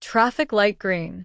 traffic_sign_green.wav